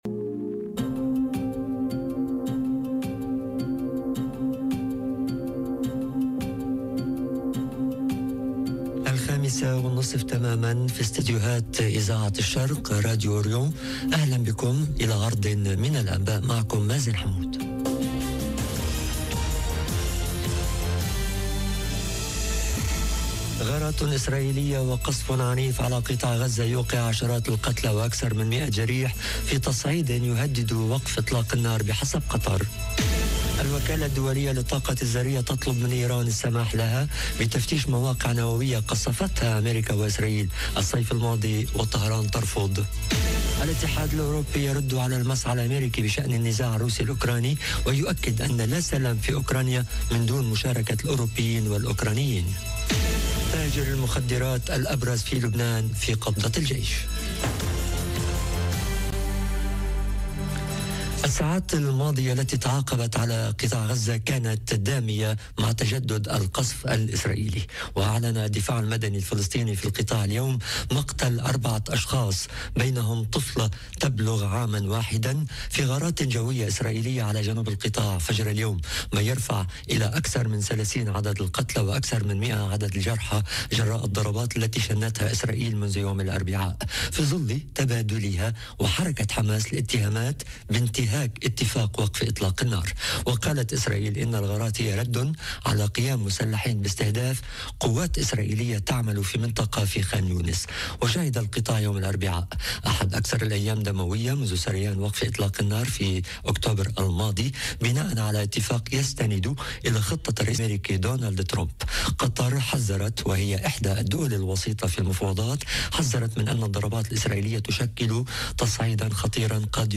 نشرة أخبار المساء: غارات وقصف على غزة، الوكالة الذرية تطلب من إيران السماح لها بتفتيش مواقع قصفتها أميركا - Radio ORIENT، إذاعة الشرق من باريس